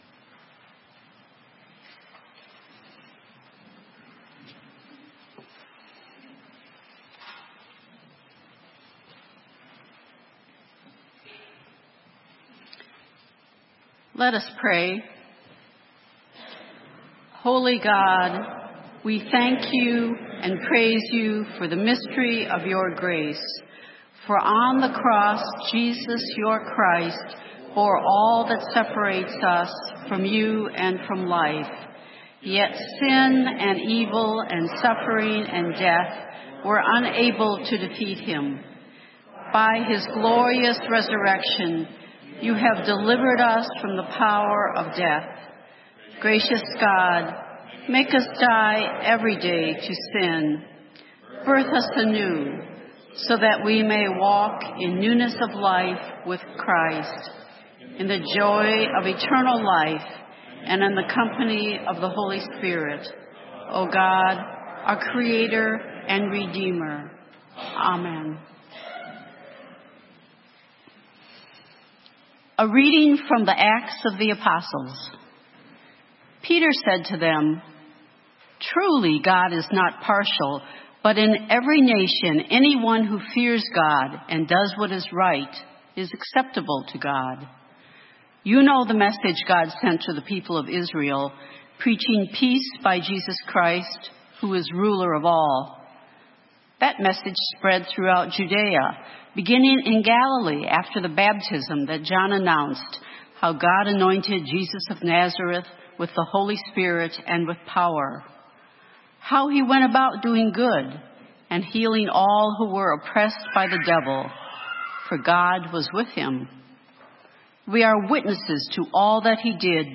Sermon:Raised with Christ - St. Matthew's UMC